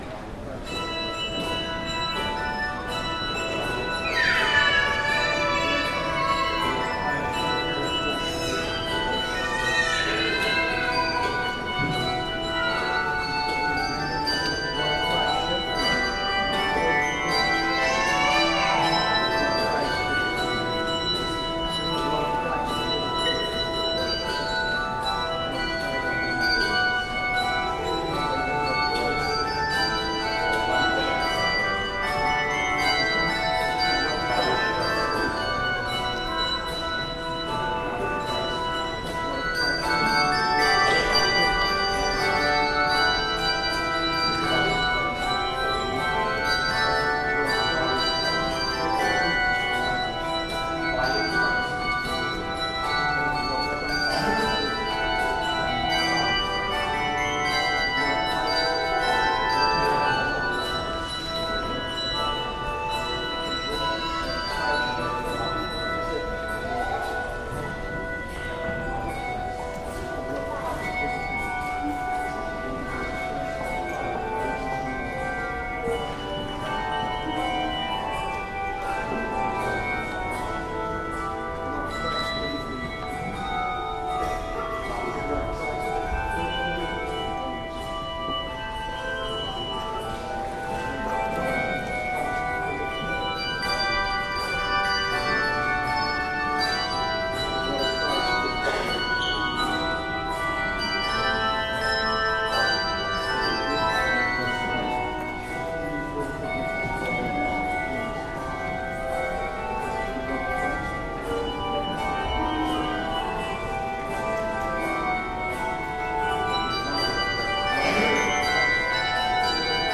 Handbells - Fantasy on Ebenezer
Handbells-Fantasy-on-Ebenezer.mp3